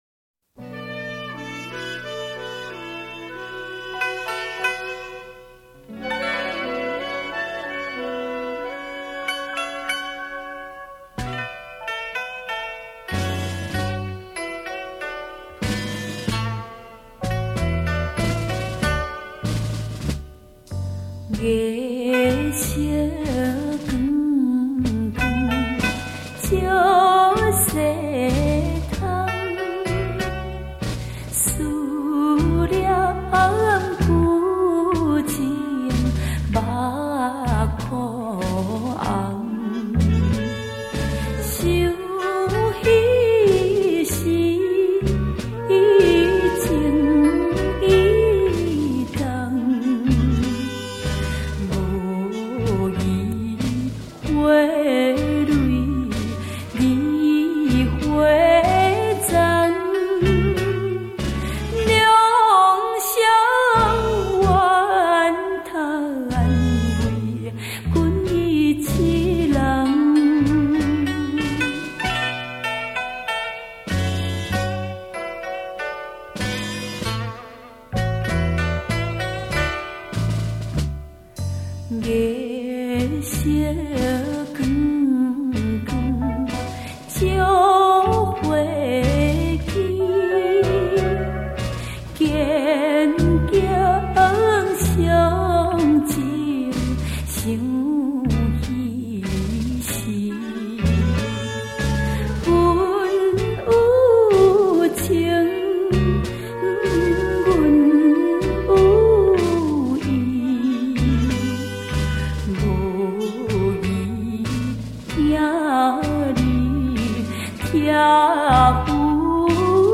值得一提的是这张唱片并非狗尾续貂 而是更值得一听的“民谣新唱”
配音和制作上都保持一气呵成的协调感 不会让曲与曲之间出现争相表现参差不齐的瑕疵